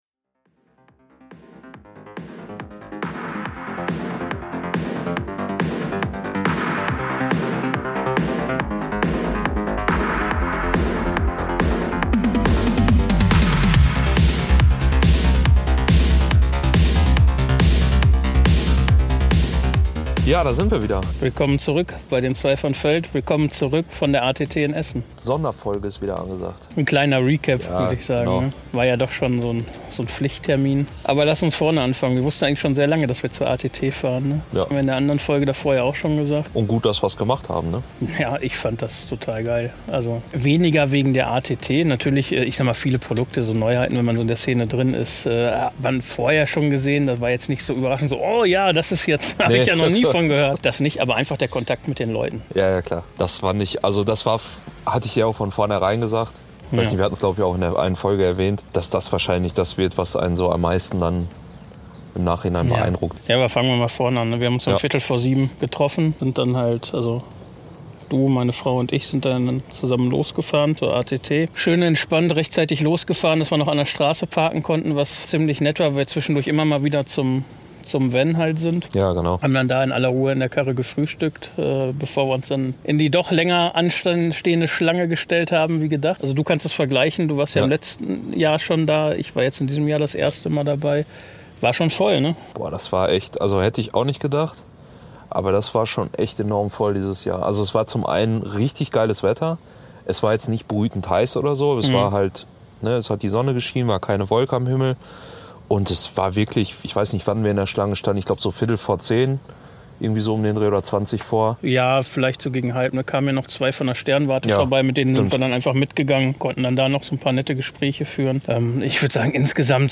In dieser kurzen Sonderfolge nehmen wir Euch mit zu unserem ersten großen Astronomie-Highlight des Jahres. Hört rein, wenn wir beim gemütlichen Plausch auf dem Feld unsere Eindrücke teilen, Gedanken schweifen lassen – und vielleicht sogar erste Ideen für einen eigenen Stand auf der ATT spinnen.